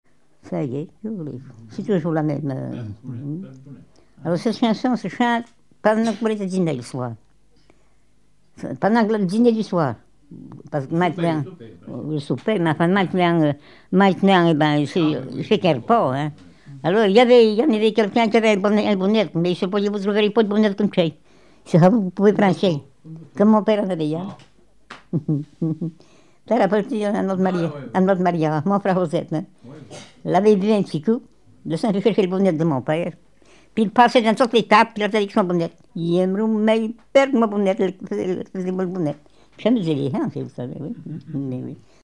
Animation du banquet de noce avec la chanson Y aimero mu perde mon bounett
Témoignage